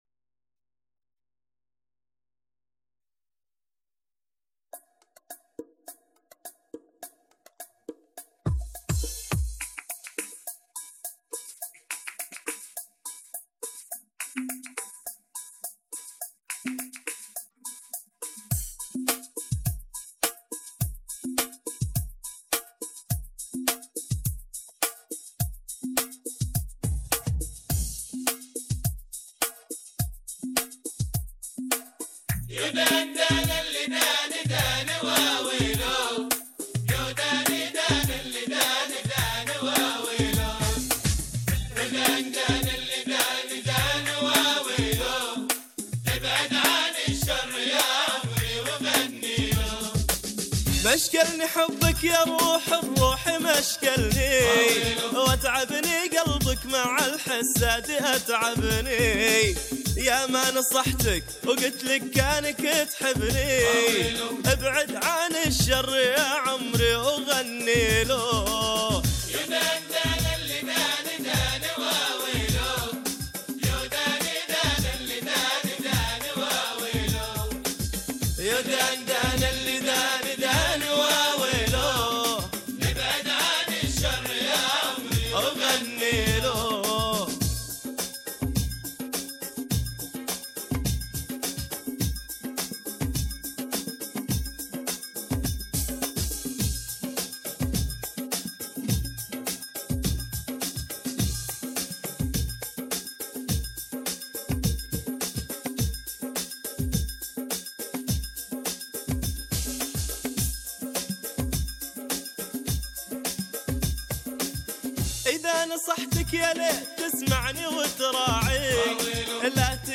أغانيك المفضلة بدون المعازف الموسيقية